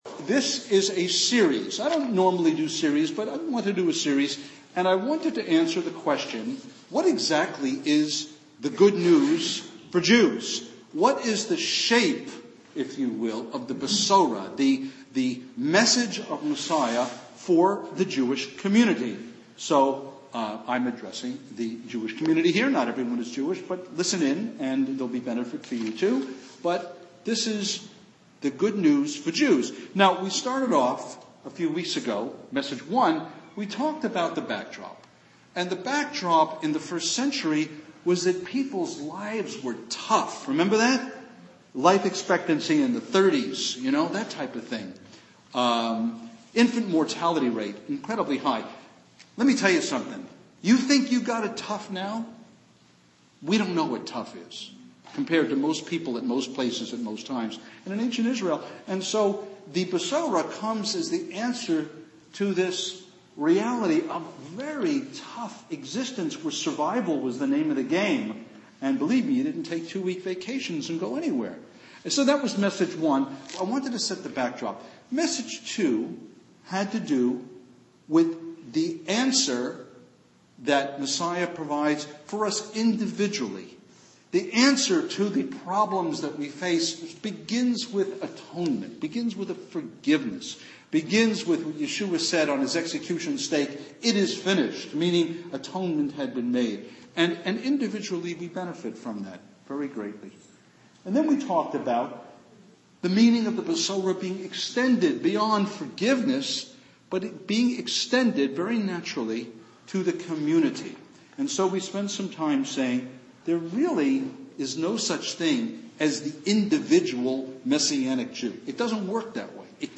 Sermon #1 – “The Way We Were” – Life back then and the prophetic promise that God will make things right